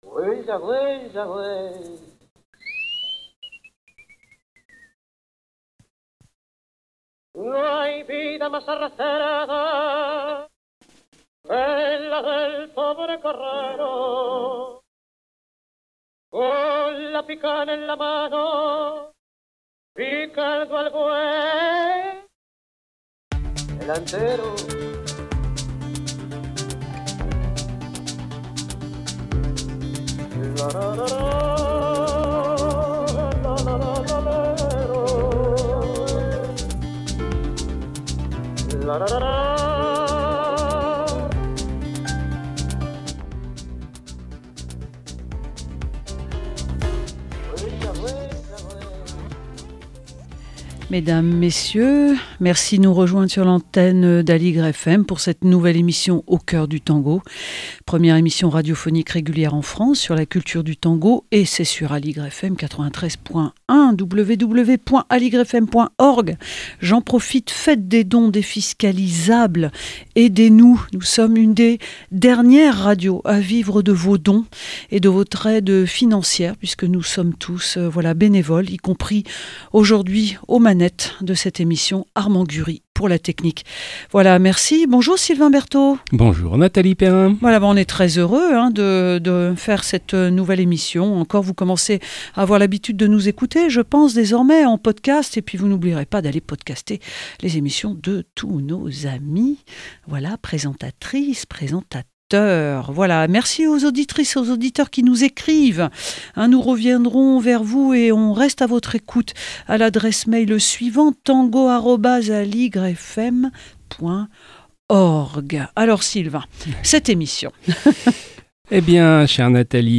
L'espace du bal de "tango" est occupé par d'autres rythmes qui participent de la culture du tango argentin : milongas et vals criollos, cortinas dansantes, chacareras et zambas...Autant d'invitations à écouter et expérimenter une variété de musiques qui forment la richesse de cette culture du tango !